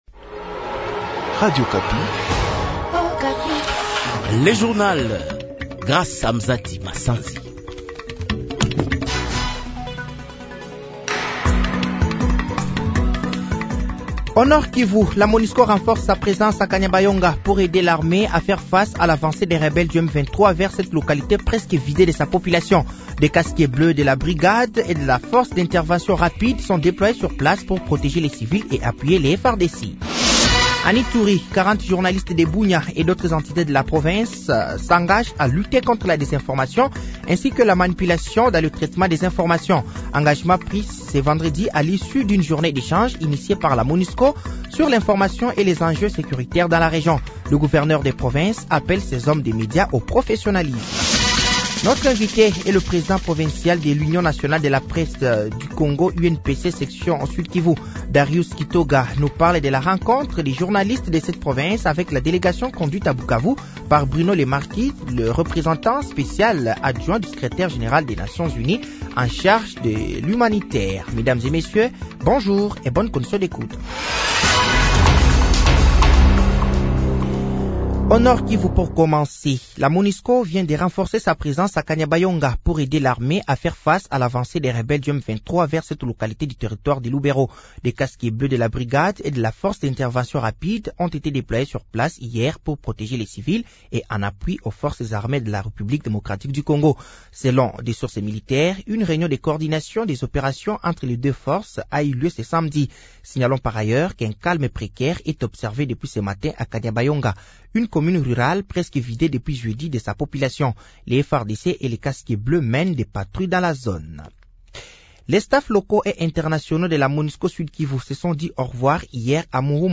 Journal français de 15h de ce samedi 01 juin 2024